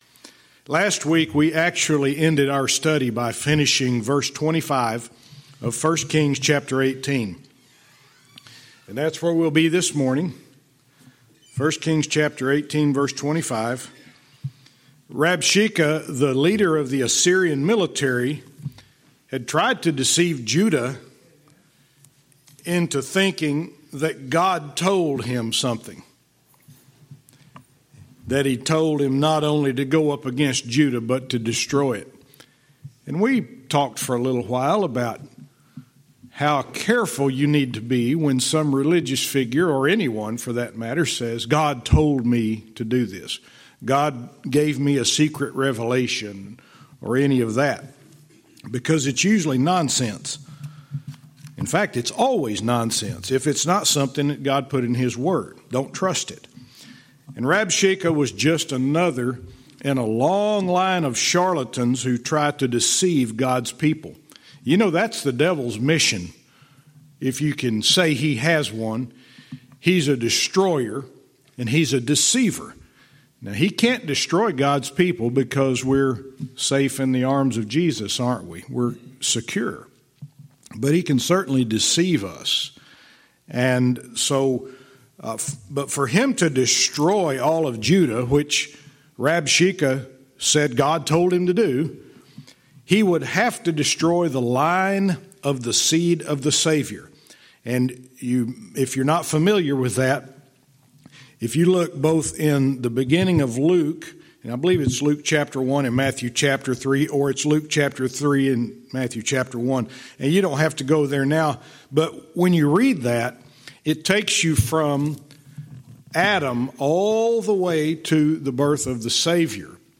Verse by verse teaching - 2 Kings 18:25-29